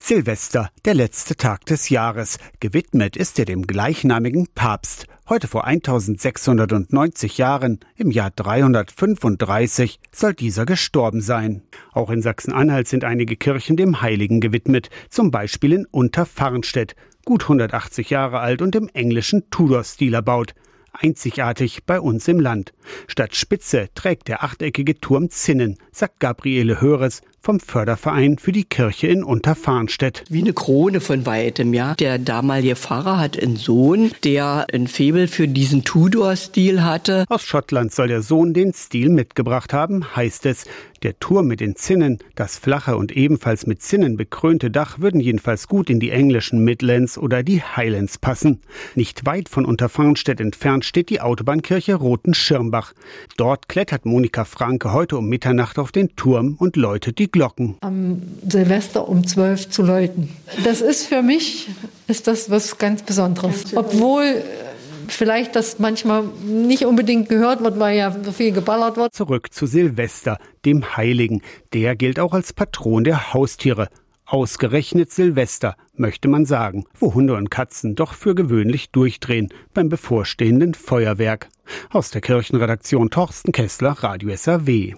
iad-radio-saw-glockengelaeut-und-jahresabschluss-in-sachsen-anhalts-kirchen-44391.mp3